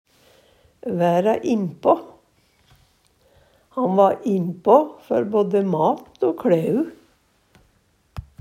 væra innpå - Numedalsmål (en-US)